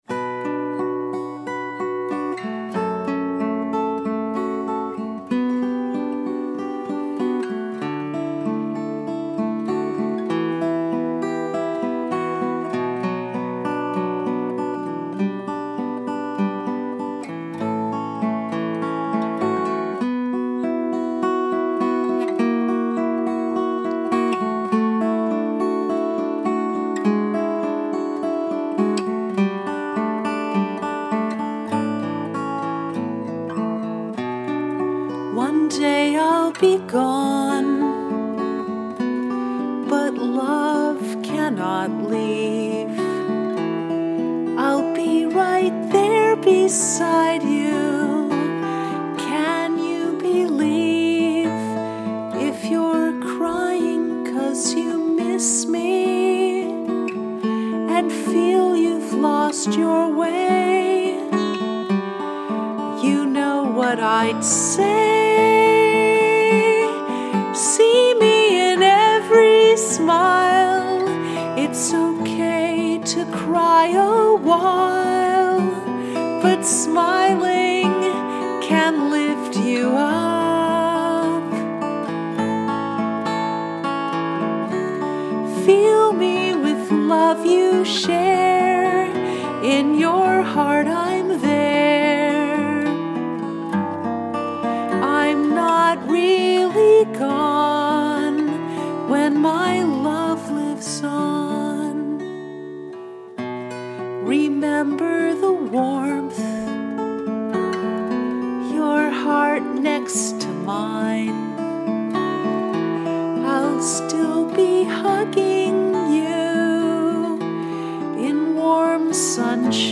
in-every-smile-acoustic-mix-2.mp3